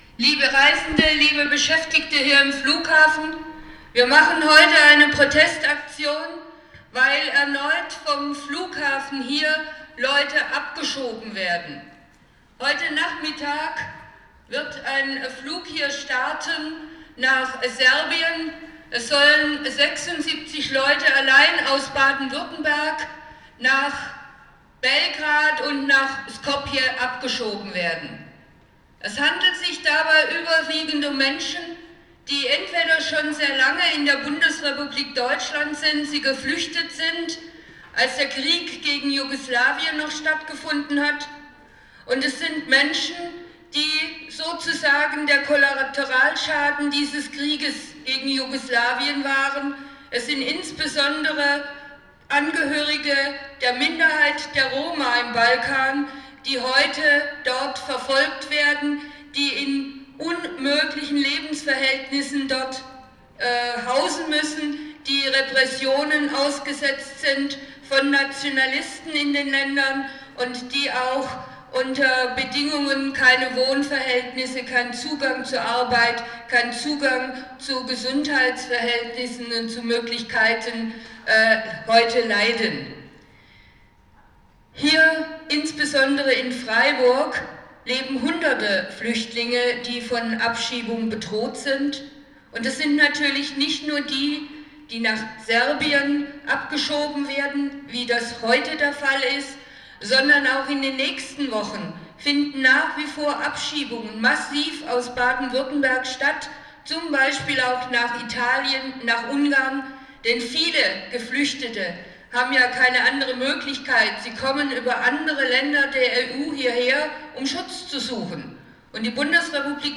In diesem Beitrag werden die ganztägige Protestaktion sowie die Abschiebung zusammengefasst, mit Auszügen aus den Redebeiträgen.